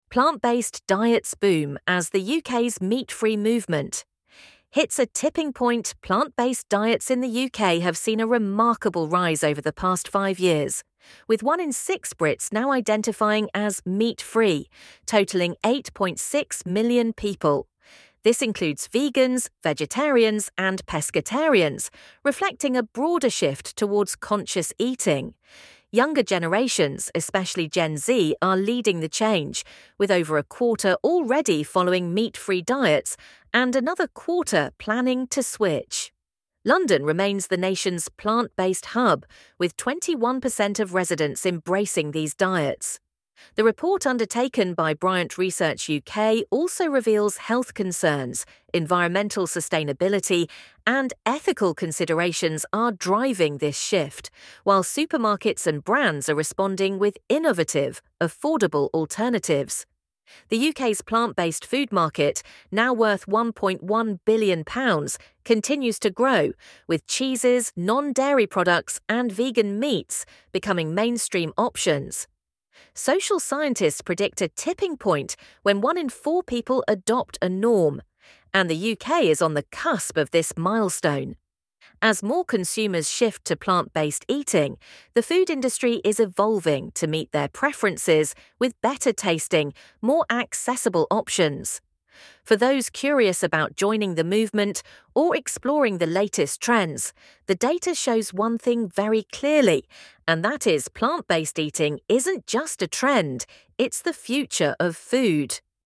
LISTEN TO THIS NEWS ARTICLE 1Min:45Secs